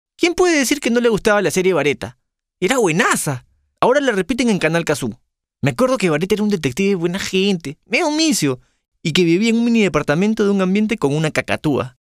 Ich habe mein eigenes Studio.